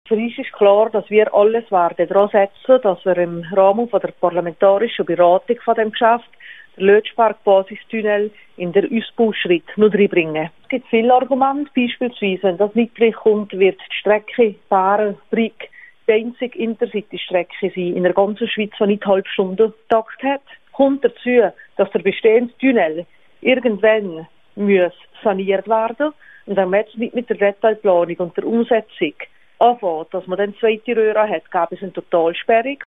Viola Amherd zur Fertigstellung des Lötschberg-Basistunnels (Quelle: rro) Interview mit Thomas Egger, Nationalrat und Direktor der Schweizerischen Arbeitsgemeinschaft für Ber (Quelle: rro)